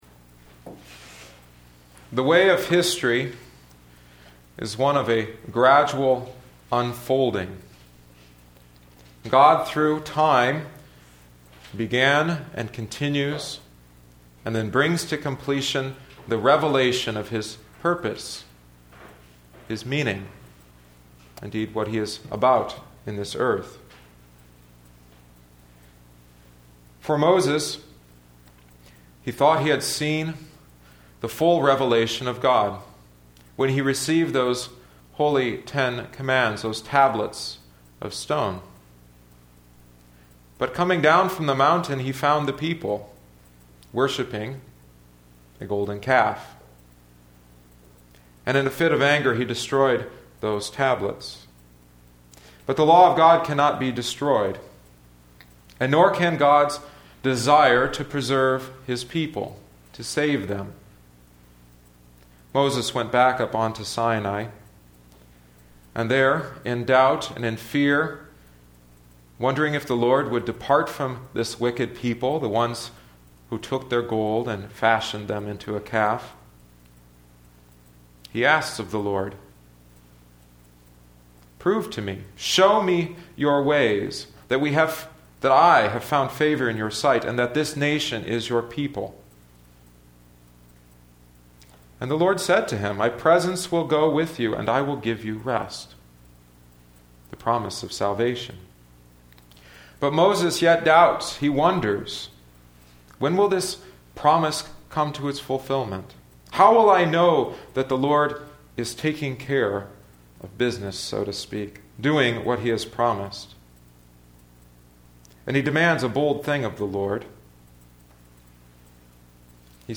Listen to the sermon: Divine Service 2012-01-14